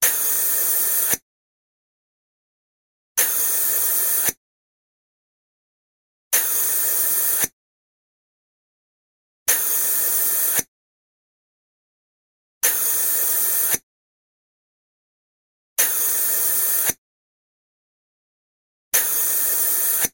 Звуки противогаза
Шум кислородного аппарата спасателей